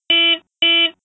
honk.wav